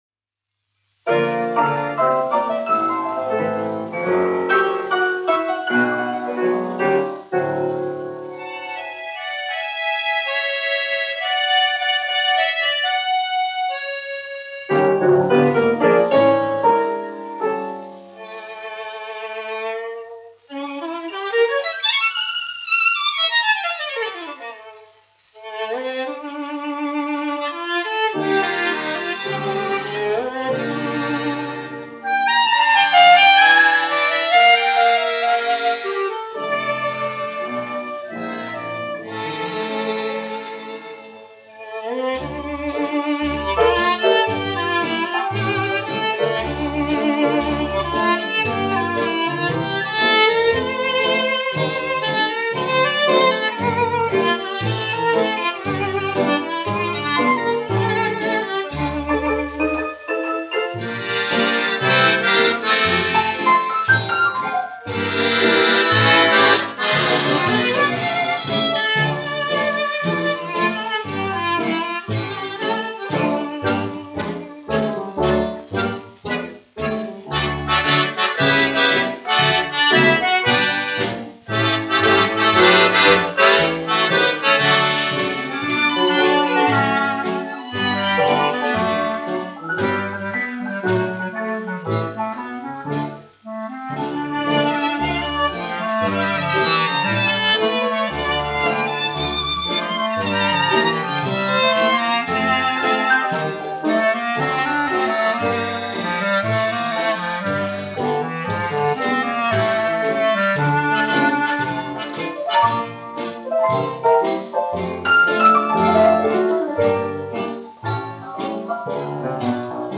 1 skpl. : analogs, 78 apgr/min, mono ; 25 cm
Džezs
Populārā instrumentālā mūzika
Latvijas vēsturiskie šellaka skaņuplašu ieraksti (Kolekcija)